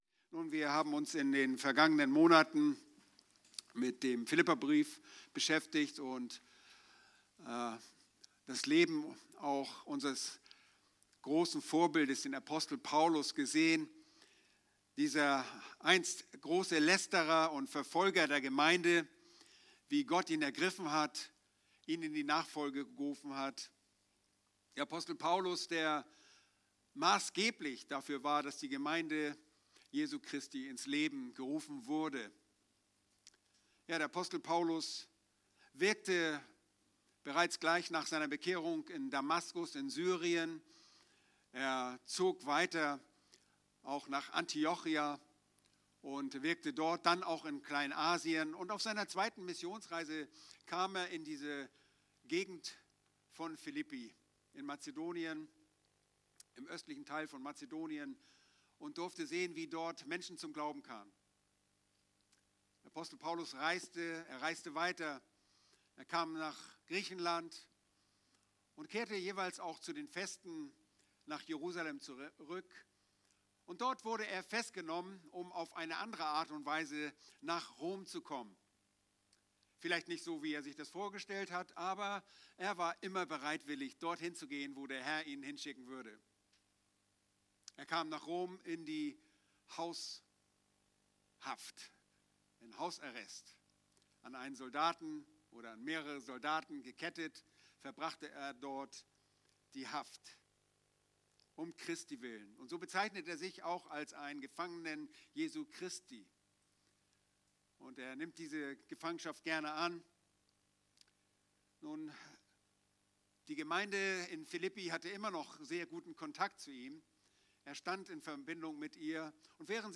In dieser Predigt